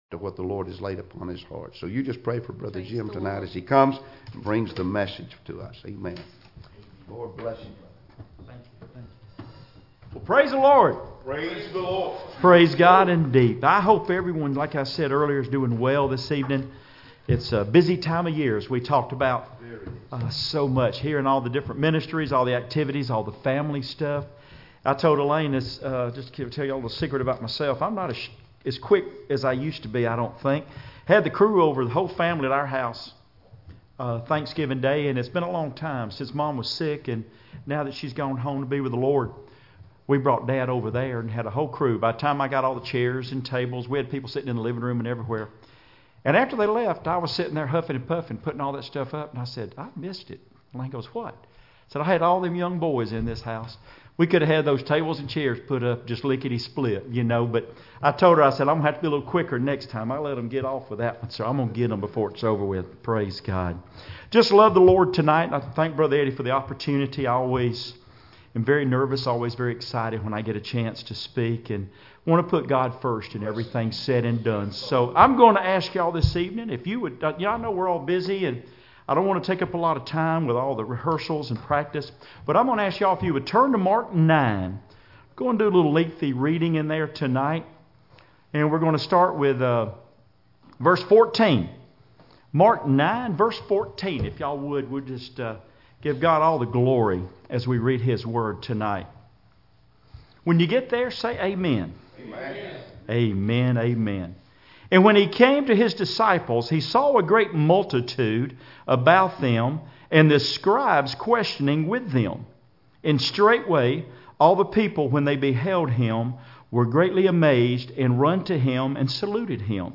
Service Type: Sunday Evening Services